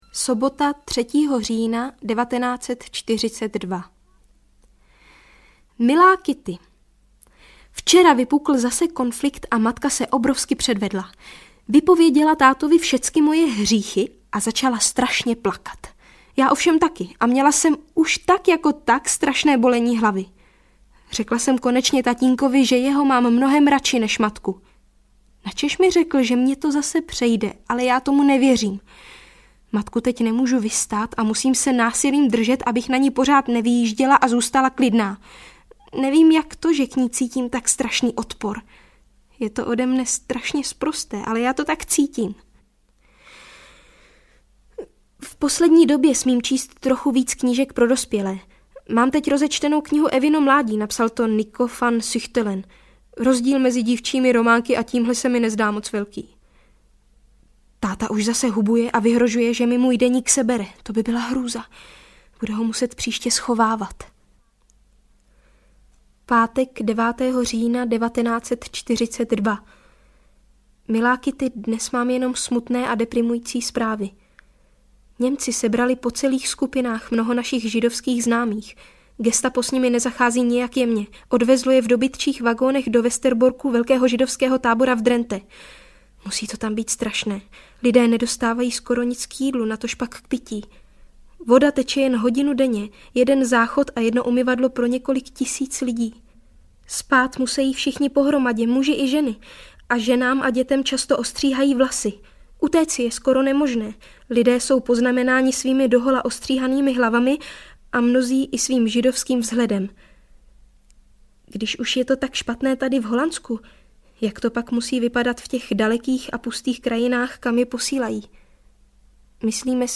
Audio kniha
Ukázka z knihy
• InterpretVěra Slunéčková